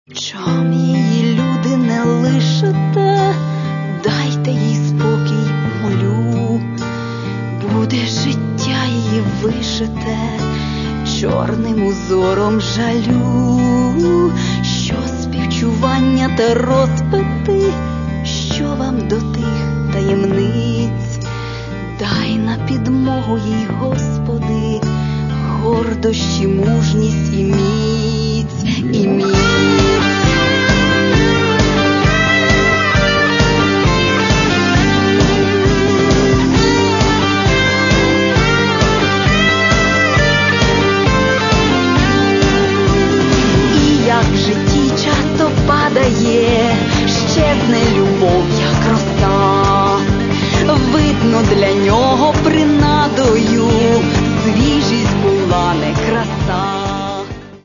Catalogue -> Modern Pop -> Lyric